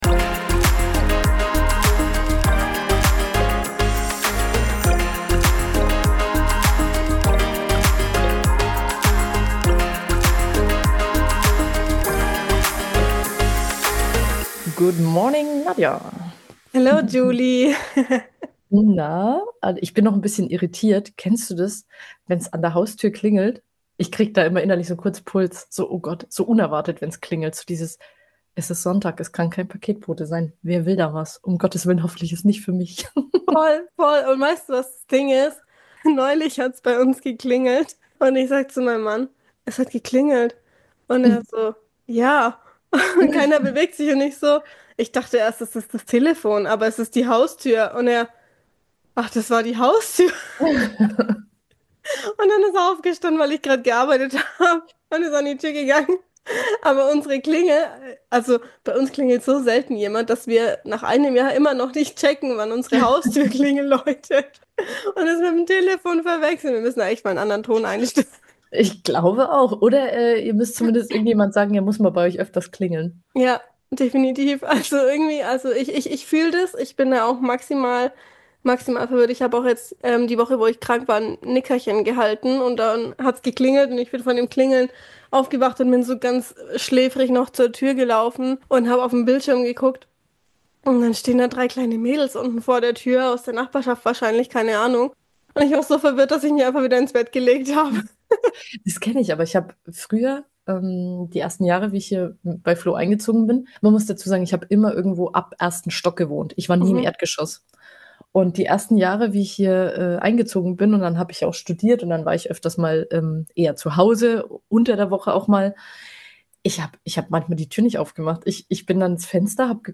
Wir nehmen dich mit hinter die Kulissen des Influencer-Daseins (Spoiler: weniger Glitzer, mehr Grübeln) und fragen uns, ob das Leben vielleicht einfach auch dann gut sein kann, wenn nicht alles ein „Leidenschaftsprojekt“ ist. Vom Anschwimmen am See, philosophischen Gedanken im Alltagstrott und einem regelmäßigen FOMO-Flash – diese Folge ist ehrlich, laut und ein bisschen therapeutisch.